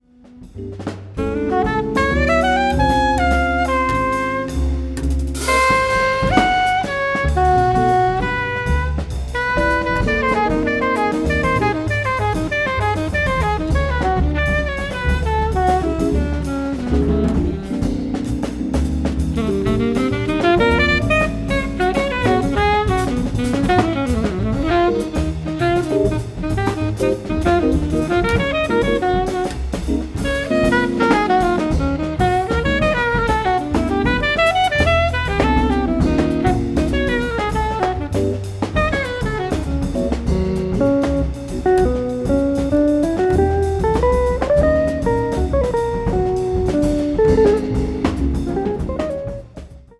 clarinet, bass clarinet, alto saxophone
electric guitar
Fender Rhodes, piano, synths
drums
acoustic and electric bass